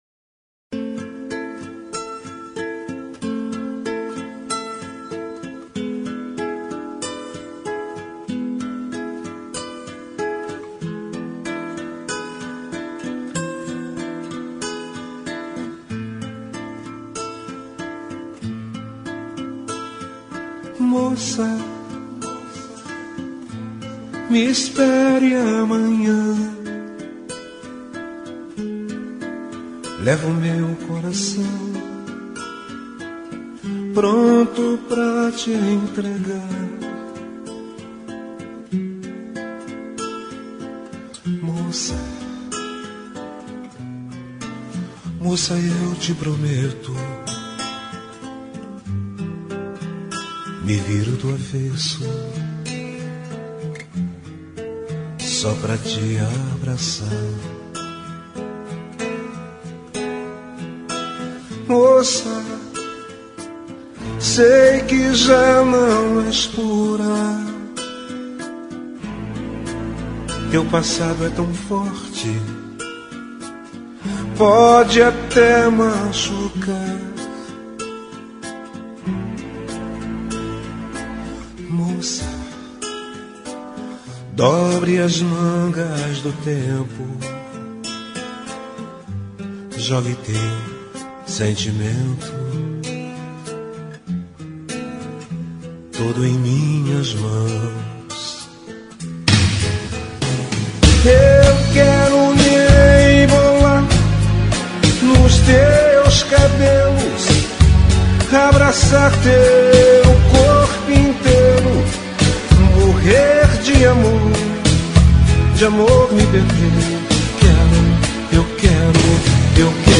Brega e Forro